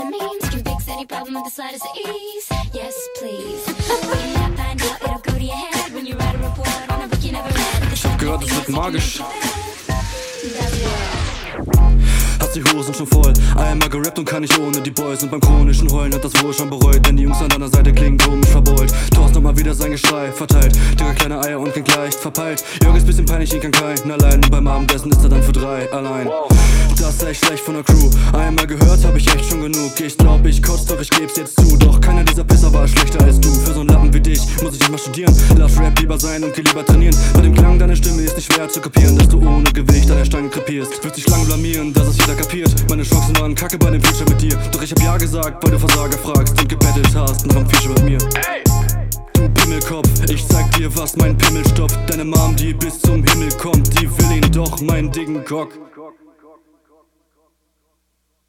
Der Beat ist so absolut disgusting.